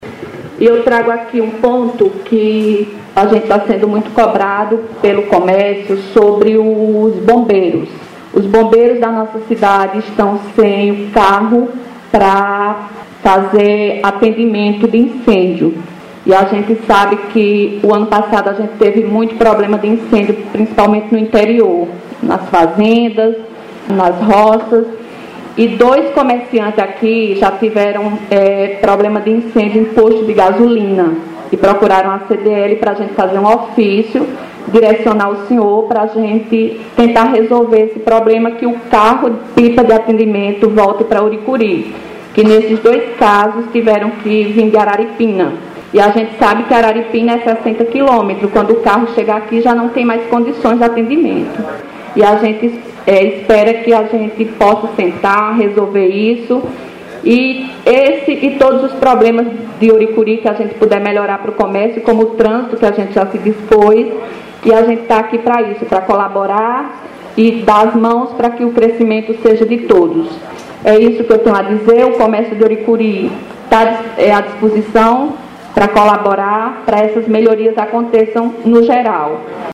O evento ocorreu no Salão Paroquial da Matriz de São Sebastião no centro de Ouricuri.